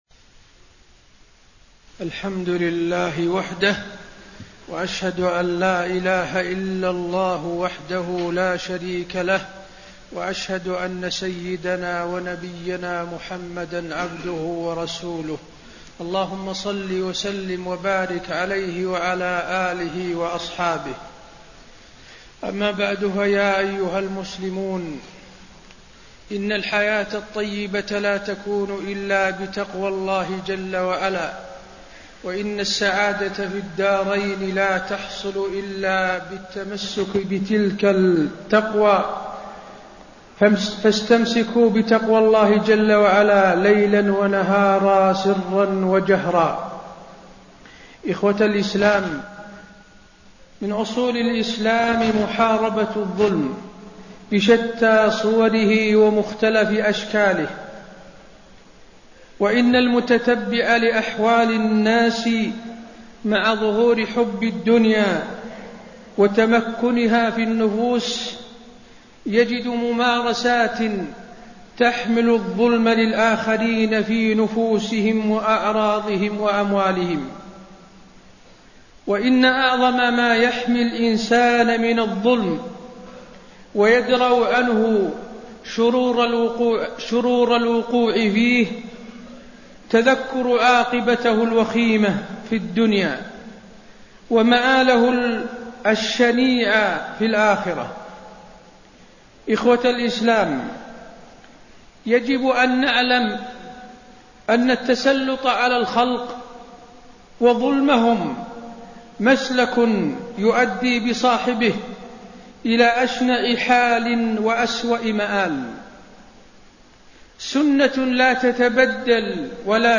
تاريخ النشر ٢٣ ربيع الثاني ١٤٣٣ هـ المكان: المسجد النبوي الشيخ: فضيلة الشيخ د. حسين بن عبدالعزيز آل الشيخ فضيلة الشيخ د. حسين بن عبدالعزيز آل الشيخ عواقب الظلم